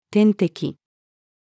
点滴-female.mp3